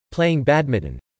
5.  playing badminton ((v phr.): chơi cầu lông ) Spelling: (/ˈpleɪɪŋ ˈbædmɪntən/ )